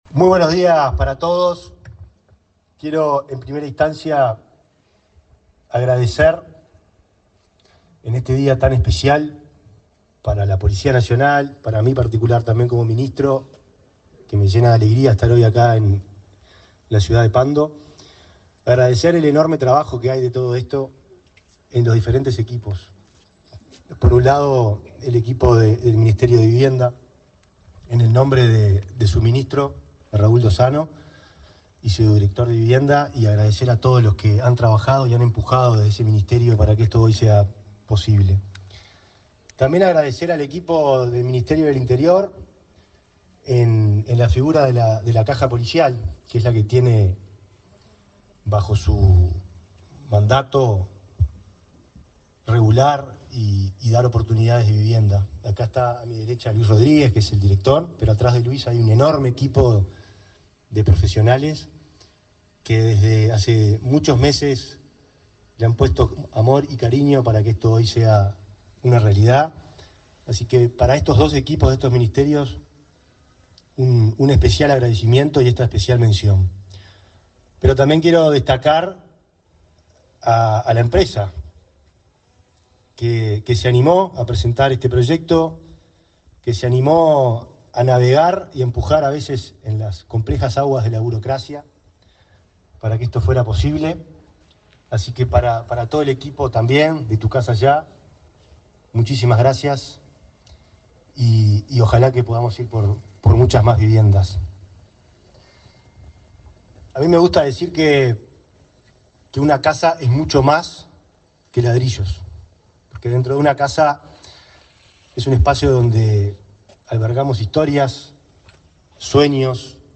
Palabras de autoridades en inauguración de viviendas en Pando
El ministro del Interior, Nicolás Martinelli, y su par de Vivienda, Raúl Lozano, participaron, este viernes 15 en Pando, en la inauguración de 12